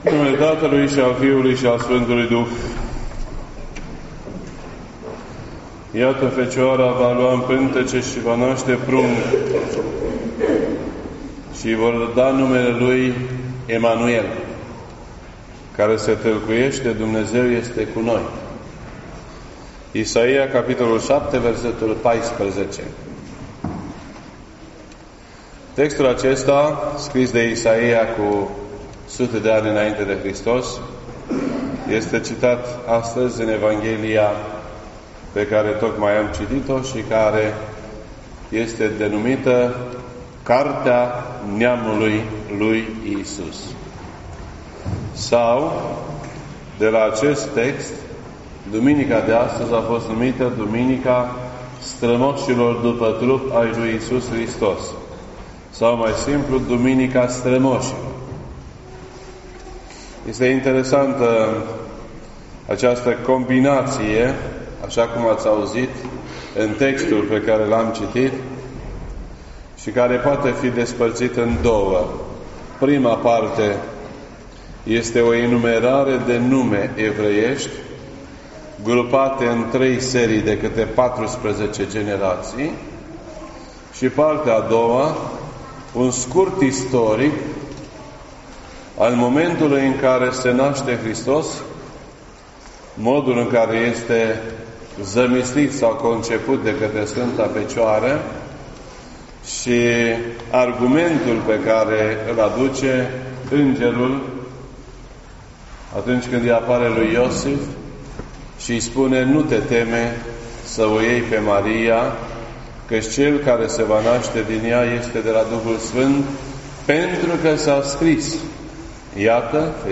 This entry was posted on Sunday, December 23rd, 2018 at 12:41 PM and is filed under Predici ortodoxe in format audio.